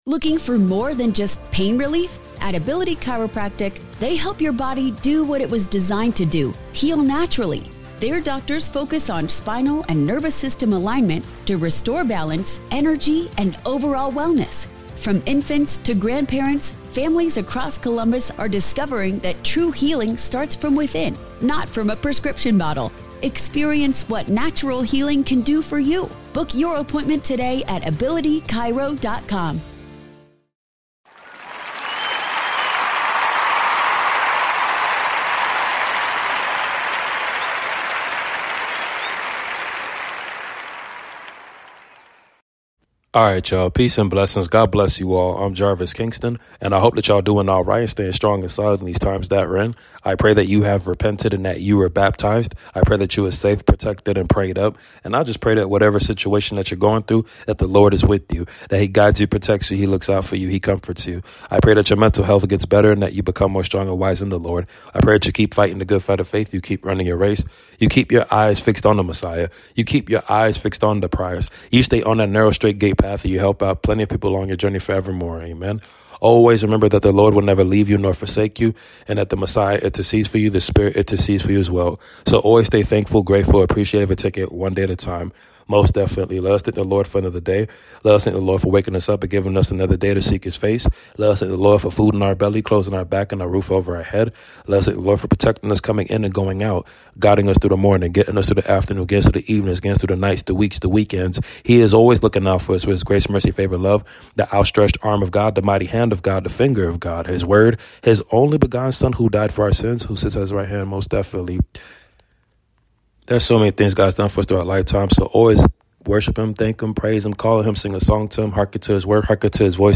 Your Nightly Prayer 🙏🏾 John 15:5